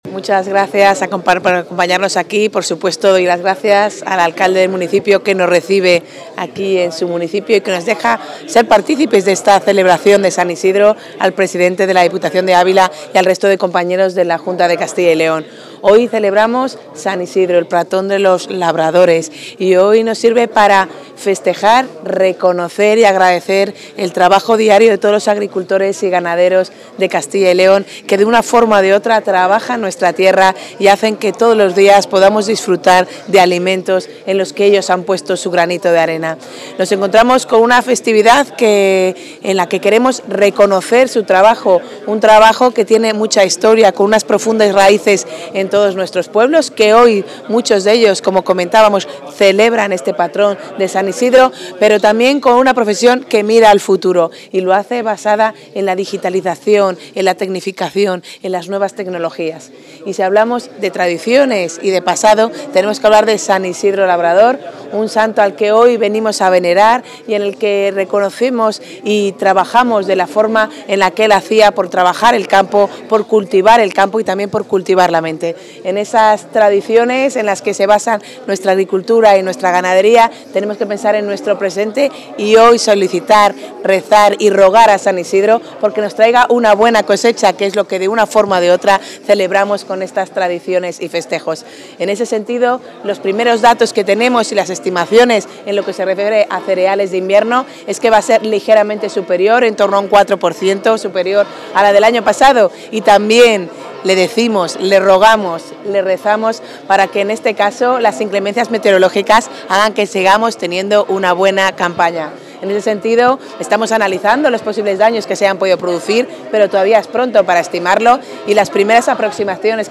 Intervención de la consejera.
La consejera de Agricultura, Ganadería y Desarrollo Rural celebra el patrón de agricultores y ganaderos en Nava de Arévalo, Ávila, donde ha indicado que el avance de superficie apunta a un incremento del 4 % en los cereales de invierno y ha mostrado su confianza en que el buen aspecto del campo se traduzca en rentabilidad para el sector.